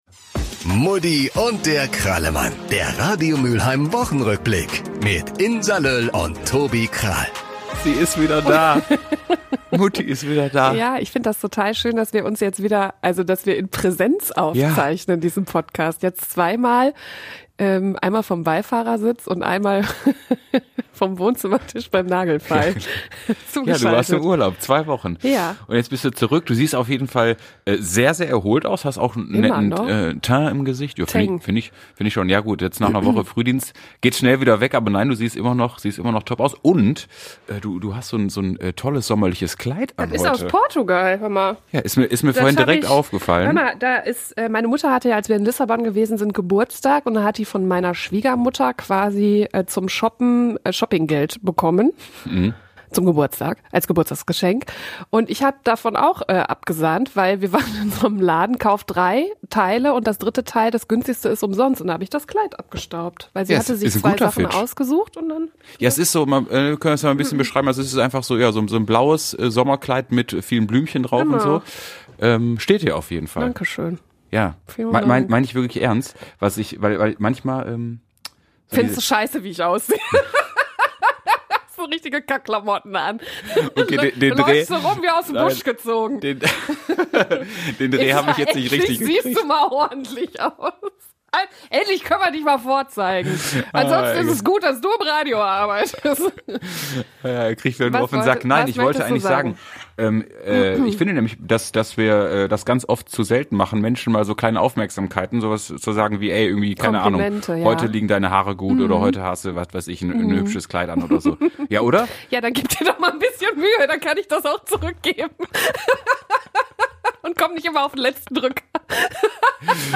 Garniert wird das Ganze - wie so oft - mit einem saftigen Deep-Talk übers Jagen...